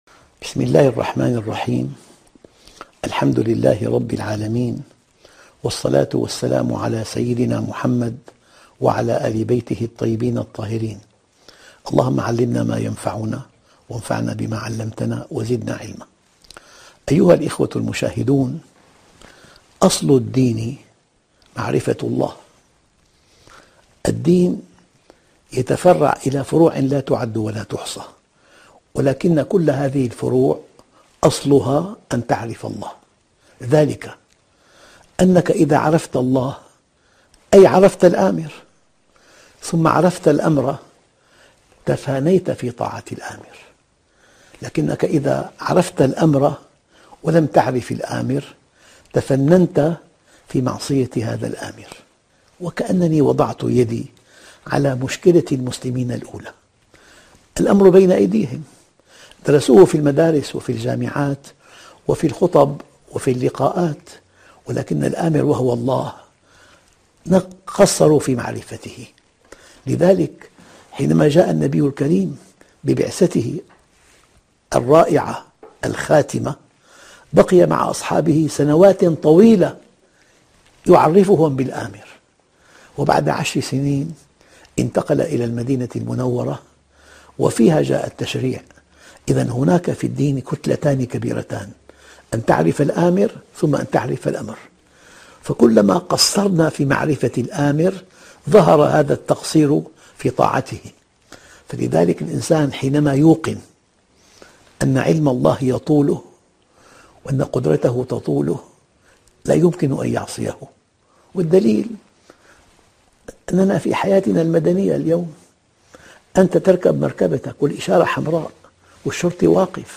شبكة المعرفة الإسلامية | الدروس | معرفة الله |محمد راتب النابلسي